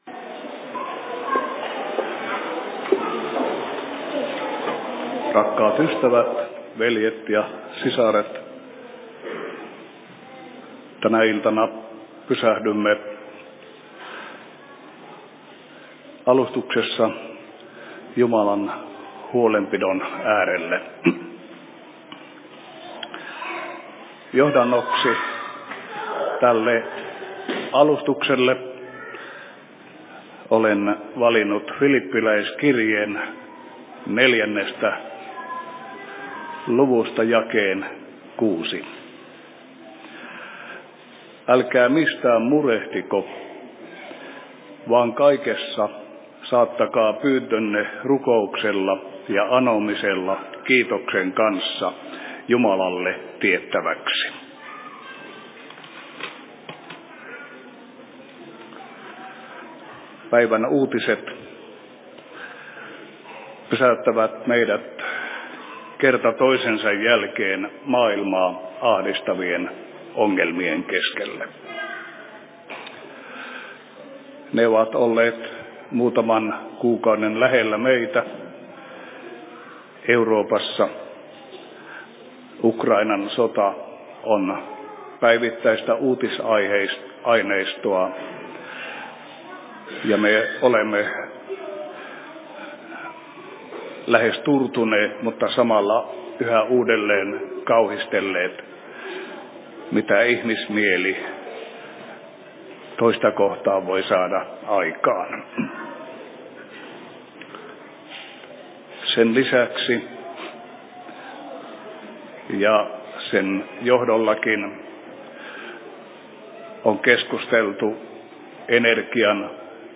Pyhäinpäiväseurat/Alustus Rovaniemen RY:llä 05.11.2022 17.30
Paikka: Rauhanyhdistys Rovaniemi